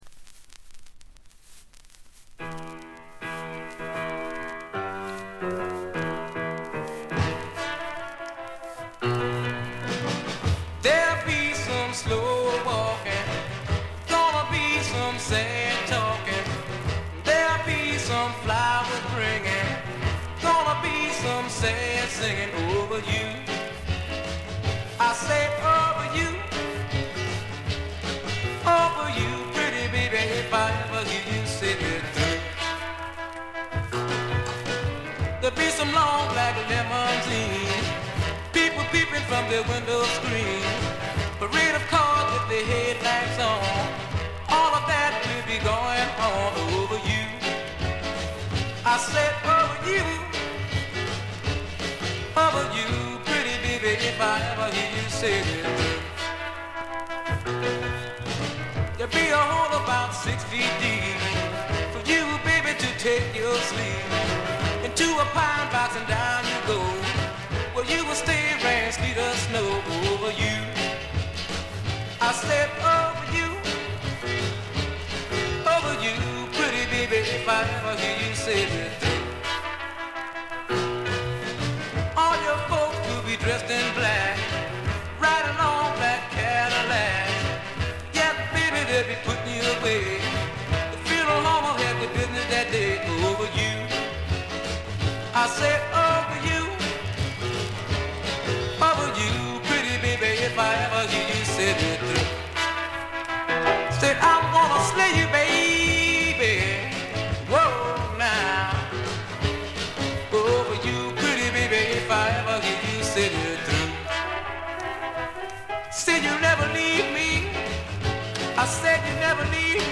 「くもり」のためか全体に軽微なバックグラウンドノイズが出ますが鮮度は良好です。ところどころでチリプチ。
試聴曲は現品からの取り込み音源です。
※A1-A2連続です。曲間のノイズをご確認ください。